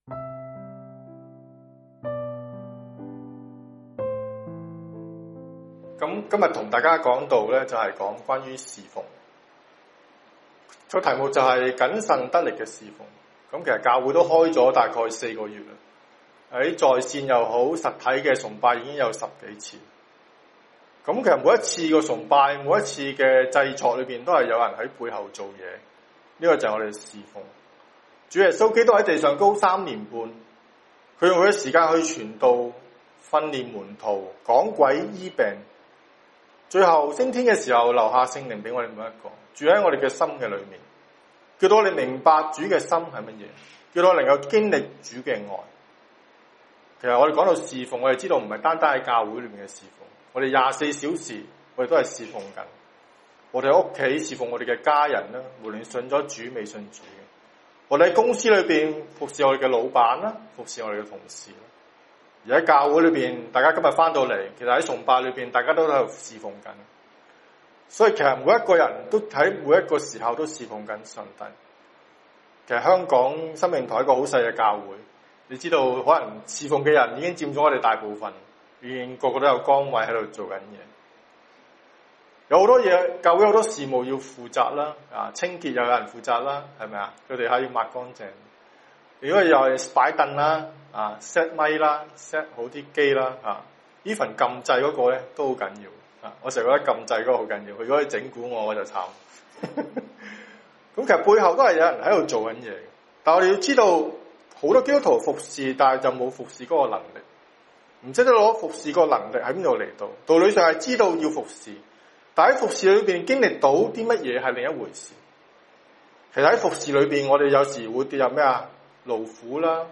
谨慎得力的侍奉[6月14日香港主日崇拜]